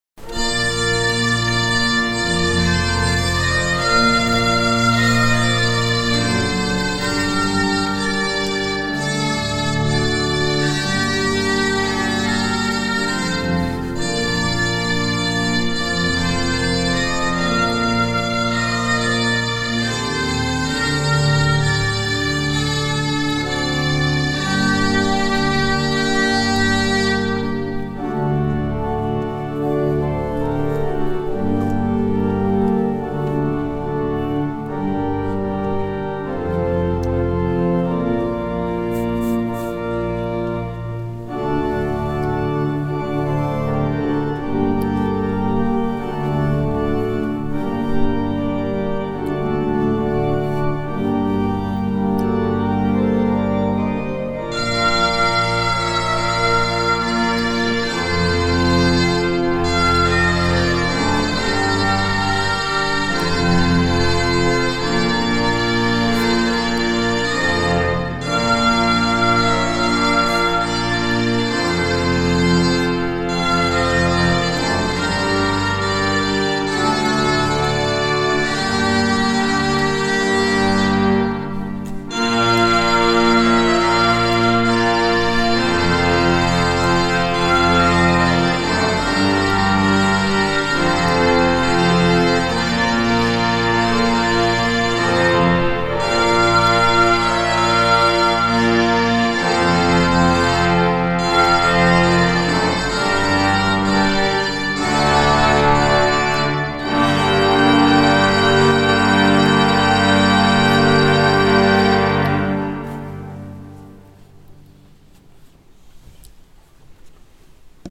Magifique concert de soutien à l’Ukraine à la cathédrale de Vannes
Un concert de solidarité pour l’Ukraine à Vannes
Mardi soir 5 avril, la cathédrale de Vannes résonnait des voix profondes du Chœur d’Hommes et de l’ensemble polyphonique du concervatoire à rayonnement départemental.
L’ambiance était particulièrement recueillie.
Hymne-Ukraine-Cathedrale-Vannes.mp3